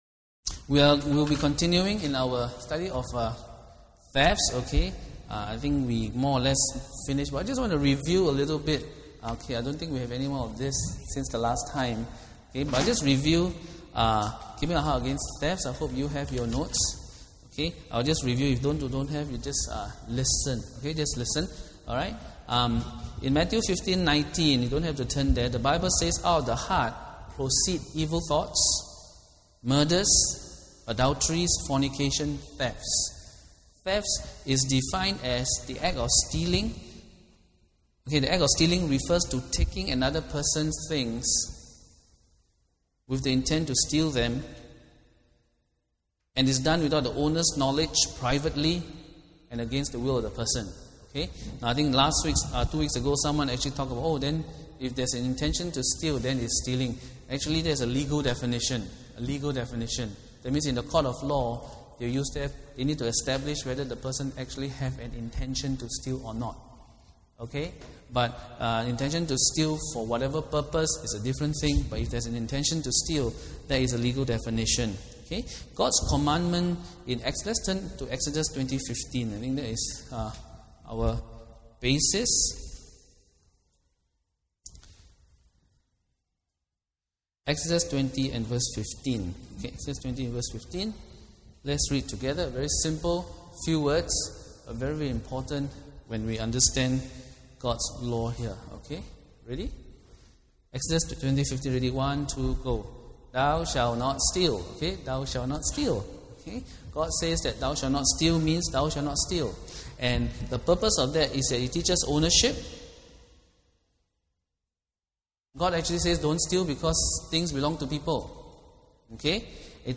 Due to a technical fault, this sermon audio is truncated.